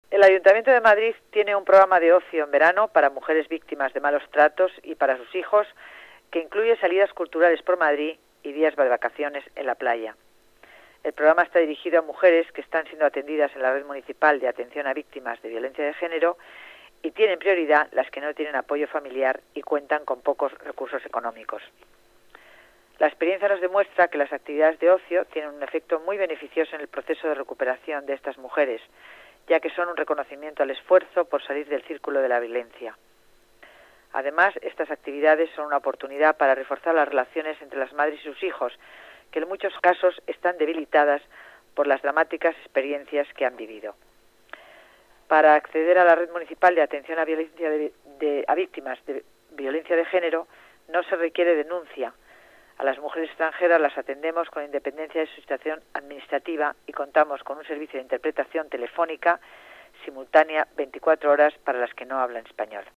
Nueva ventana:Declaraciones de de delegada de Familia y Servicios Sociales, Concepción Dancausa